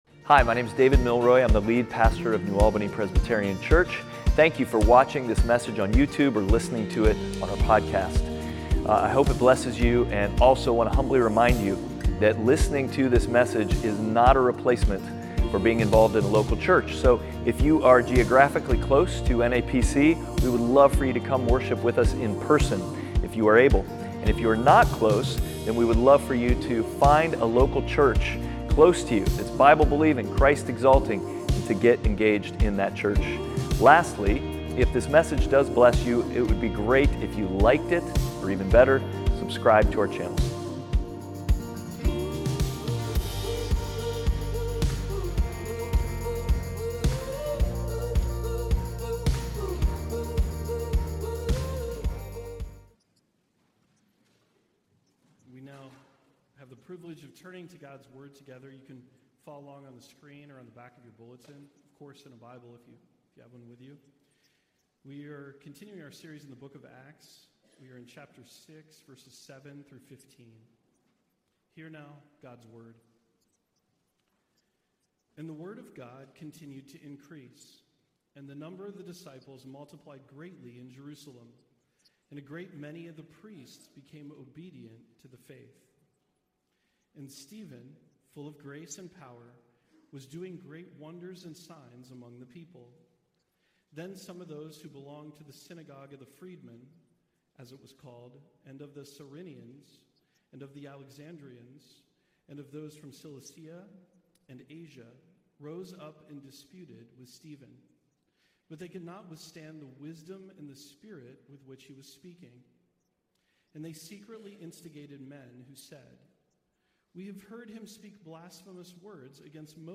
Passage: Acts 6:7-15 Service Type: Sunday Worship « The Nightmare After Christmas Outward